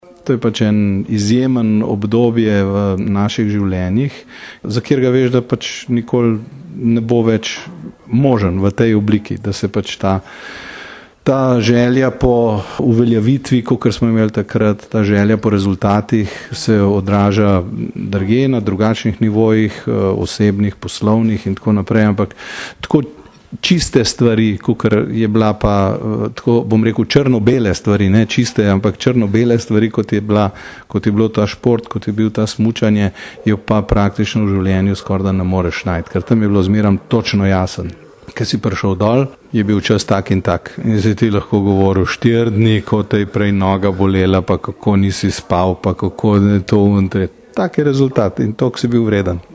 izjava_jurefranko.mp3 (1,2MB)